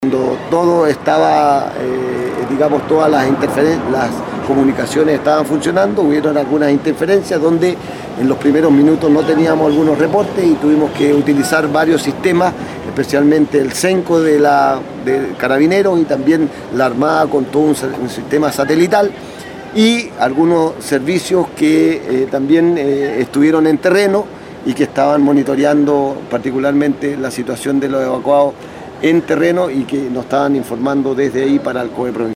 El gobernador provincial de Chiloé Fernando Bórquez detalló en números la participación en esta evacuación en la provincia.